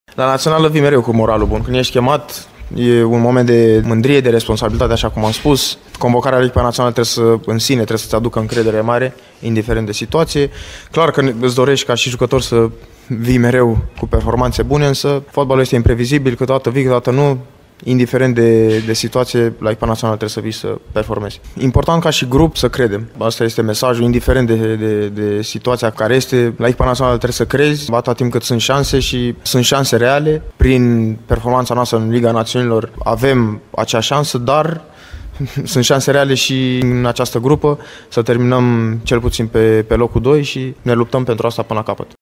Ianis Hagi se bucură să fie din nou la lot, după ce a semnat cu turcii de la Alanyaspor și chiar a marcat un gol în weekend: